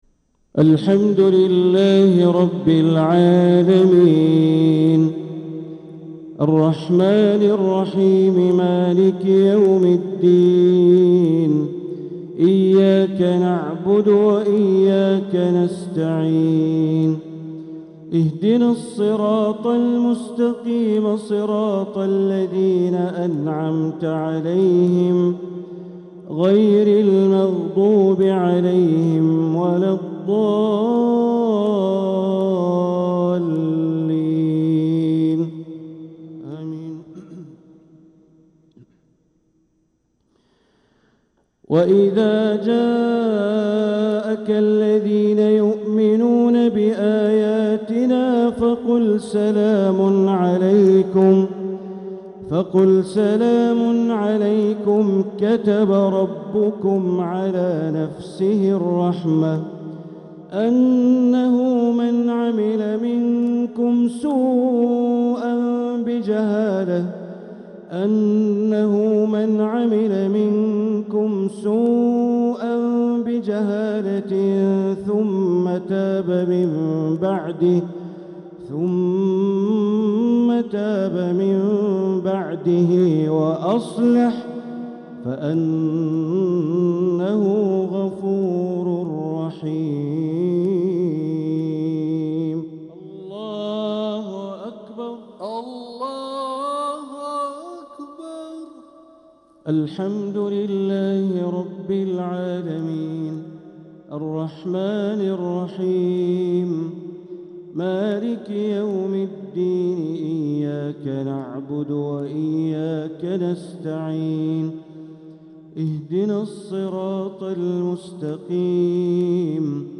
صلاة الجمعة 16 محرم 1447هـ | من سورتي الأنعام و الحشر > 1447هـ > الفروض - تلاوات بندر بليلة